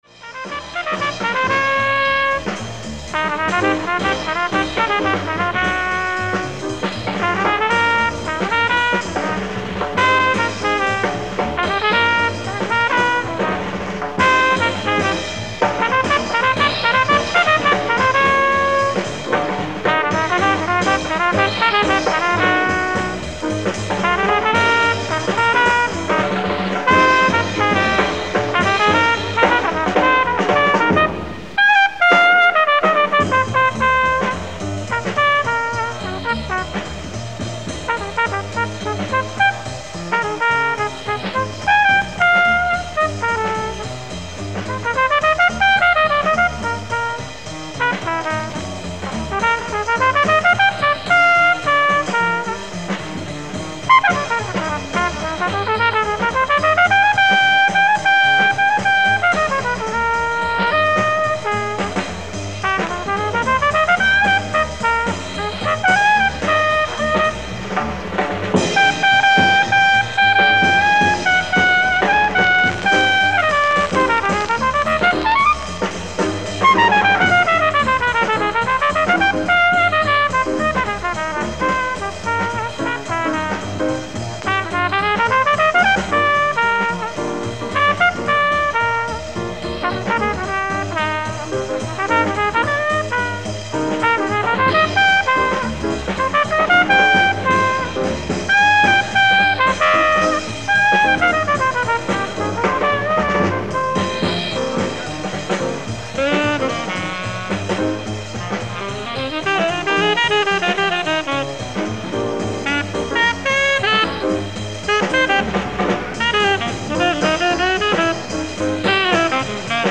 ライブ・アット・アムステルダム、オランダ
※試聴用に実際より音質を落としています。